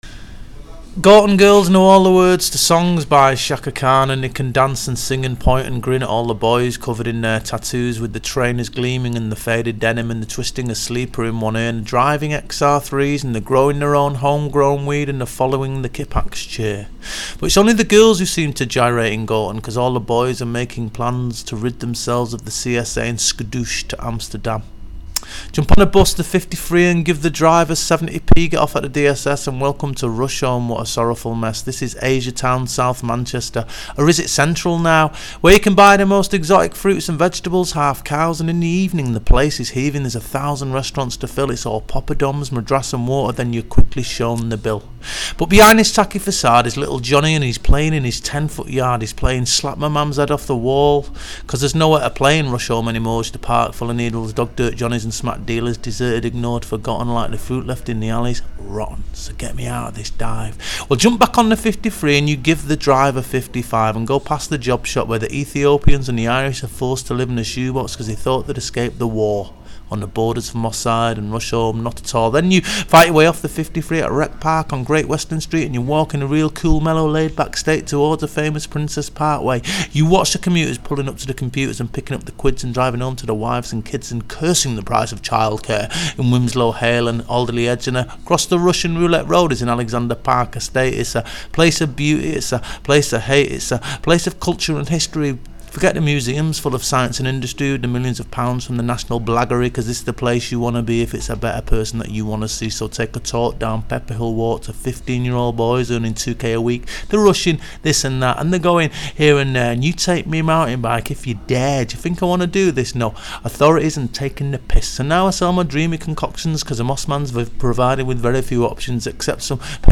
• Genre Poetry